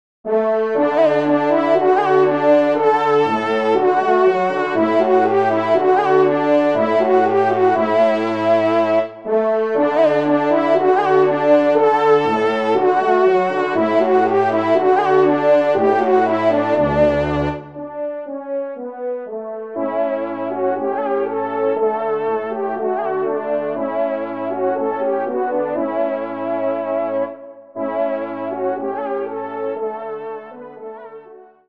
Genre : Divertissement pour Trompes ou Cors
Trompe 3